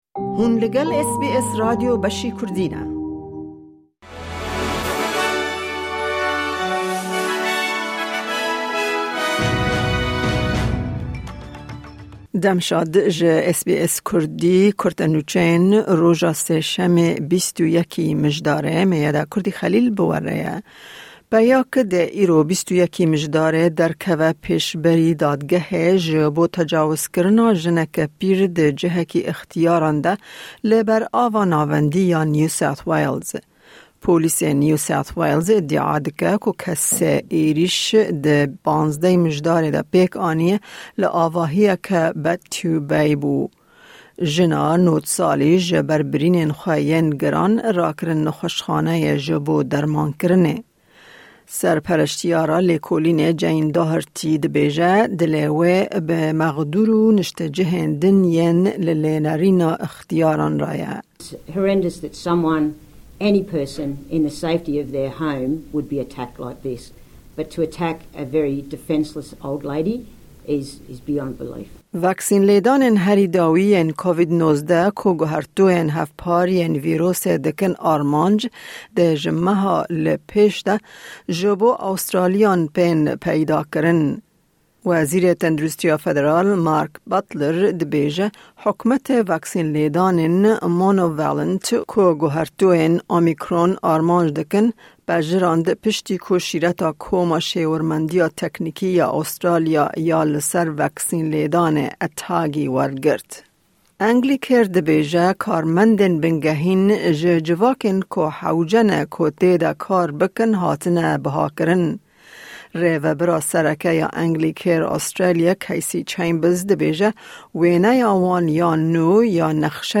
Newsflash